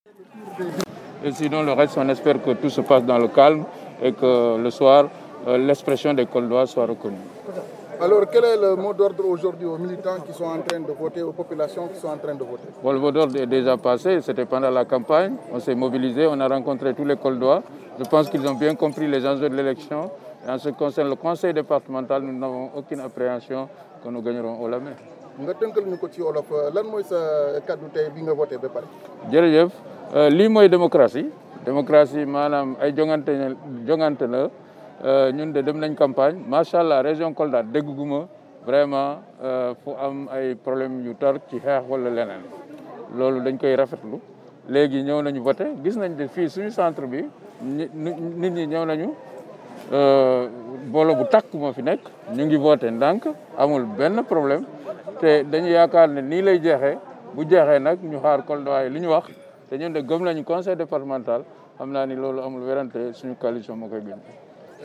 10h34mn- Le Ministre Moussa Baldé vote à l’école Amadou Michel Diop. A cette occasion, le candidat de BBY du département de Kolda se félicite de la mobilisation des électeurs, de la sérénité et de l’ambiance qui règnent dans ce lieu de vote. Le Président sortant se dit confiant que sa liste va remporter haut la main.